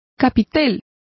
Complete with pronunciation of the translation of capital.